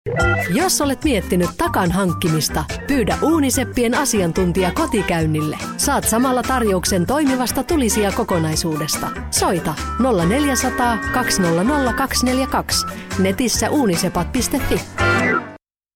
Sprecherin finnisch für TV / Rundfunk / Industrie / Werbung.
Kein Dialekt
Sprechprobe: Industrie (Muttersprache):
Professionell female finish voice over artist.